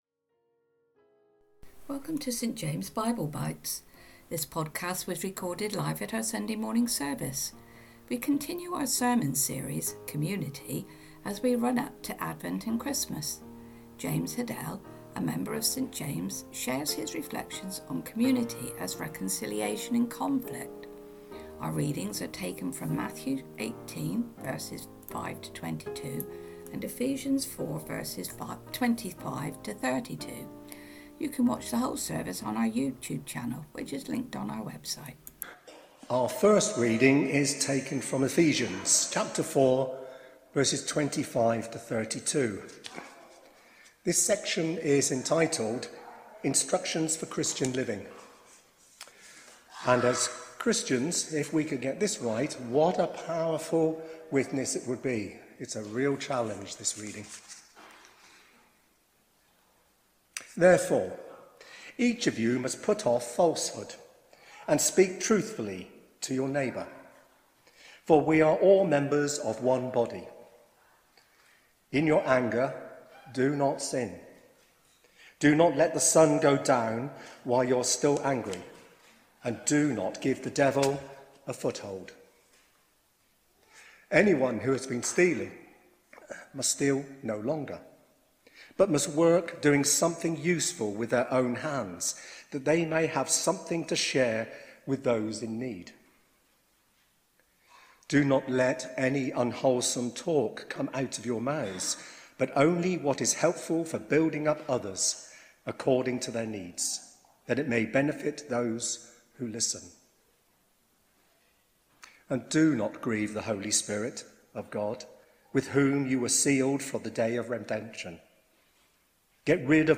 Sunday Talks Community as Reconciliation in Conflict Play Episode Pause Episode Mute/Unmute Episode Rewind 10 Seconds 1x Fast Forward 30 seconds 00:00 / 29:23 Subscribe Share RSS Feed Share Link Embed